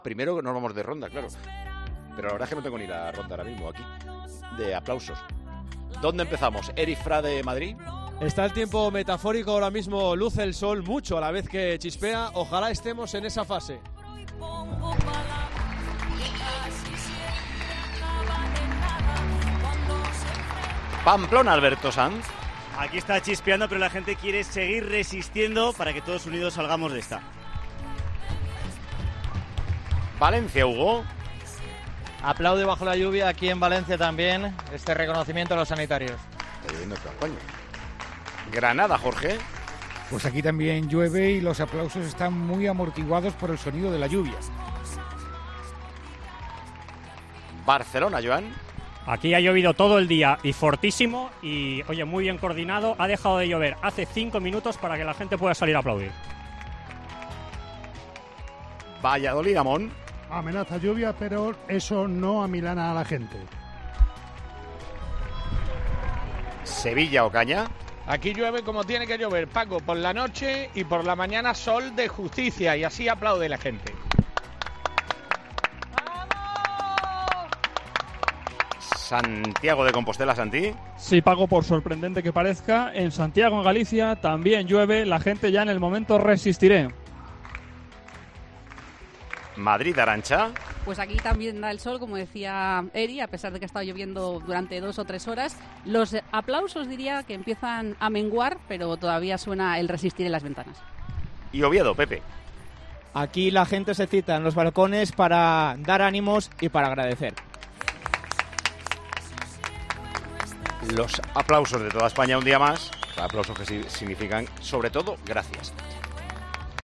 Ronda de aplausos del domingo 19 de abril de 2020
AUDIO: La lluvia no nos quita las ganas de aplaudir y de dar las GRACIAS